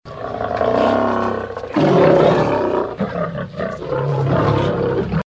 lion_roaring_dey.wav